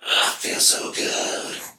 Feel So Good Whisper.wav